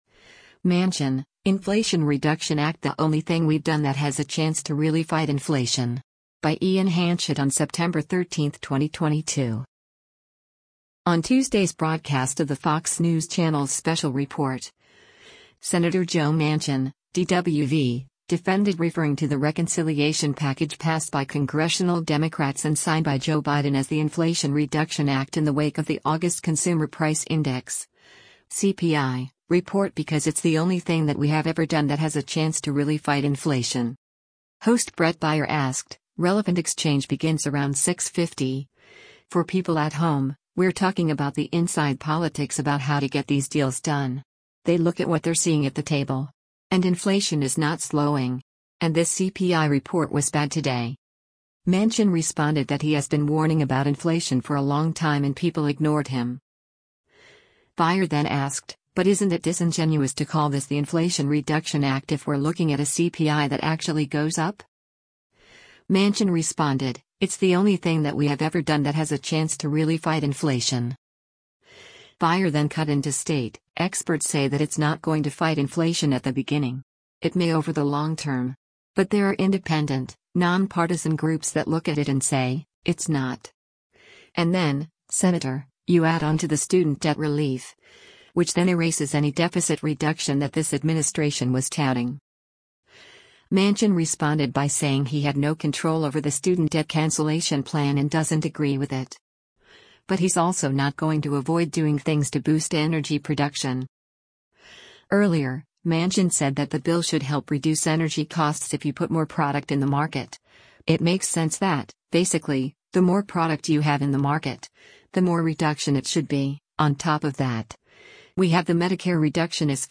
On Tuesday’s broadcast of the Fox News Channel’s “Special Report,” Sen. Joe Manchin (D-WV) defended referring to the reconciliation package passed by Congressional Democrats and signed by Joe Biden as the Inflation Reduction Act in the wake of the August Consumer Price Index (CPI) report because “It’s the only thing that we have ever done that has a chance to really fight inflation.”